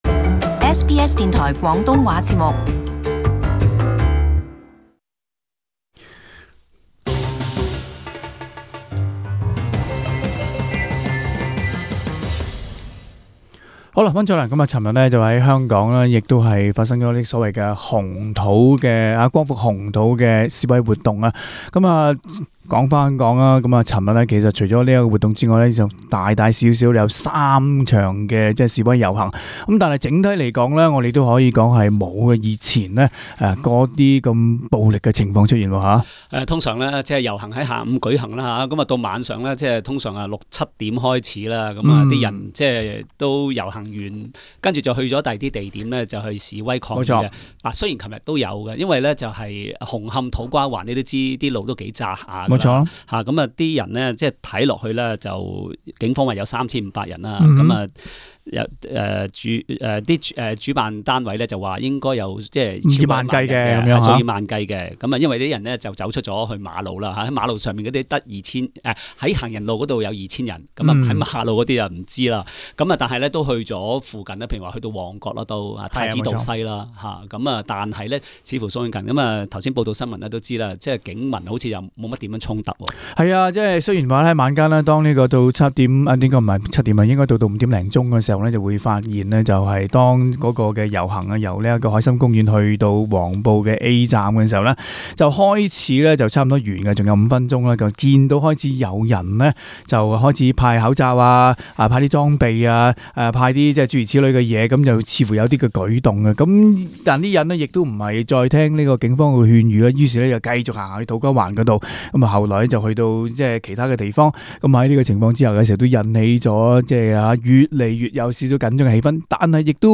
【時事報導】跟進香港示威進入第三個月